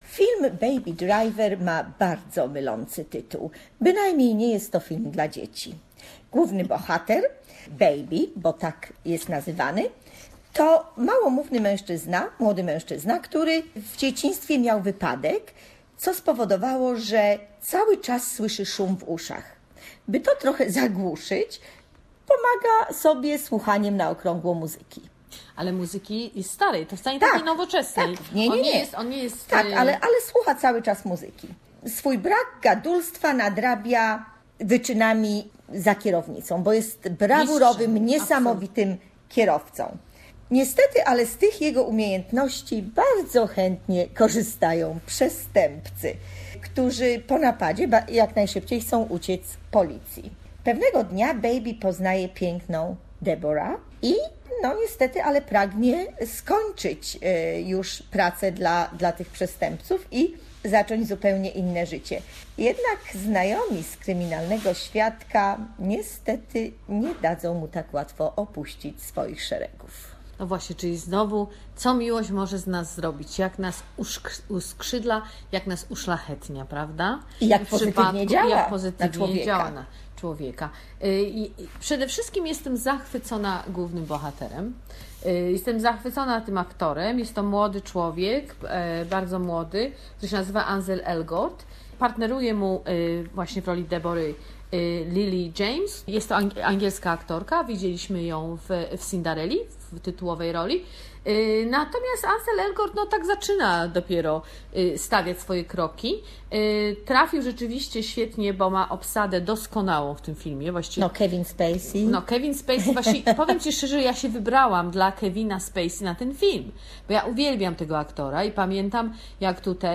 "Baby Driver" movie review